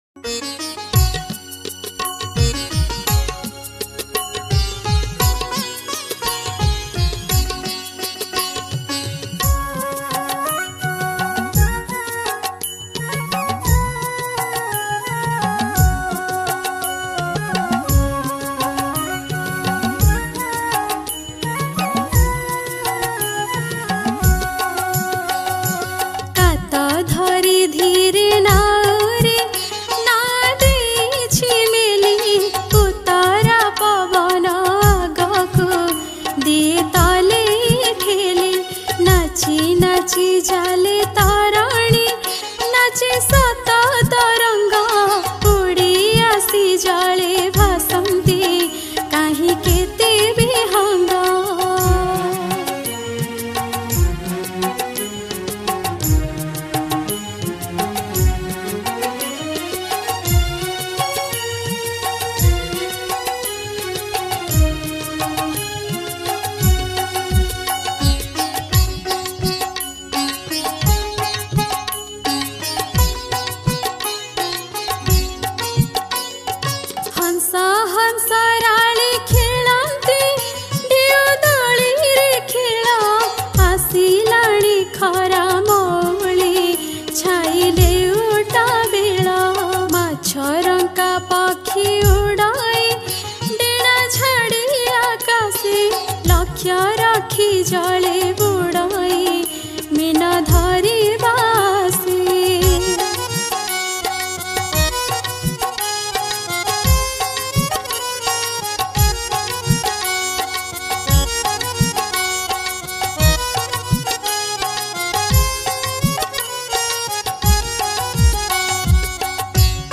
Category : Childhood Odia Song